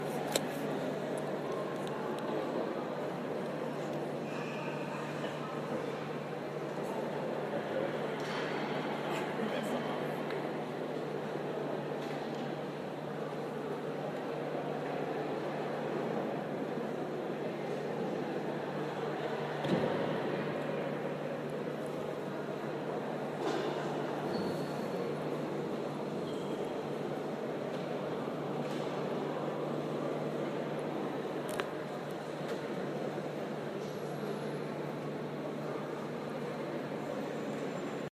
Location: The Metropolitan Museum of Art, Wednesday April 20th, approximately 4pm, overlooking Gallery 700.
Sounds heard: footsteps, echoes of voices.